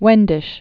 (wĕndĭsh)